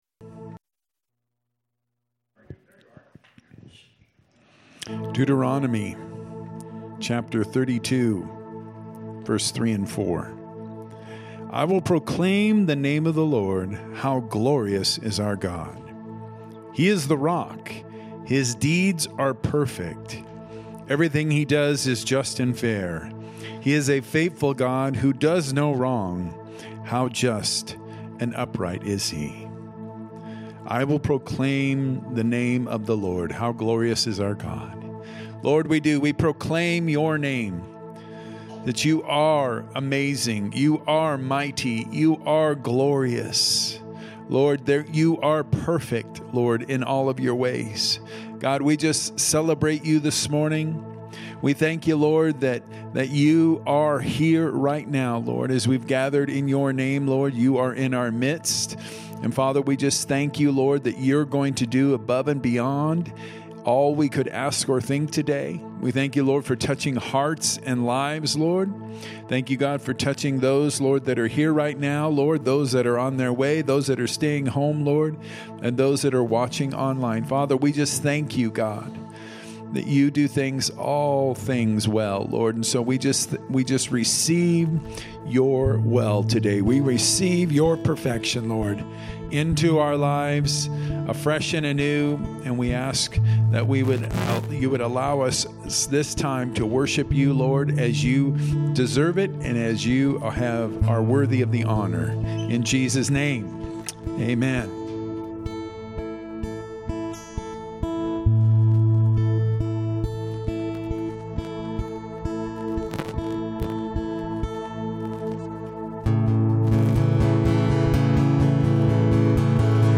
From Series: "Sermon"